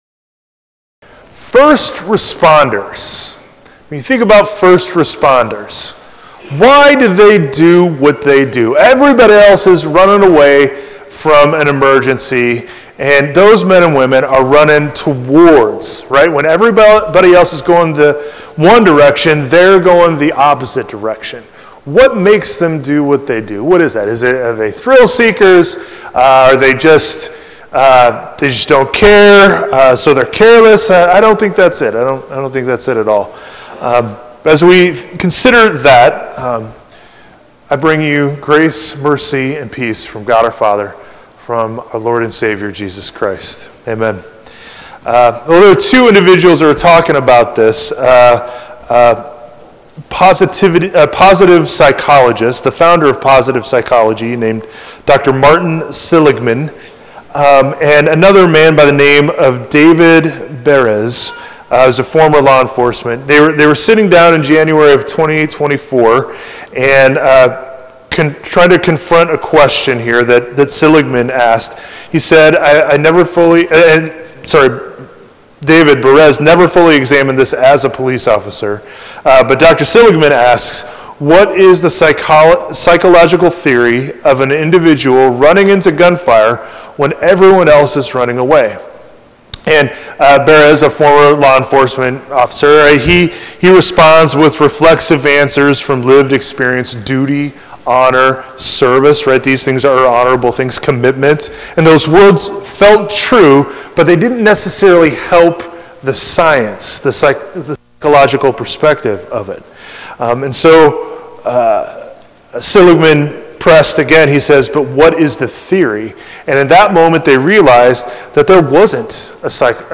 March-1-2026-sermon.mp3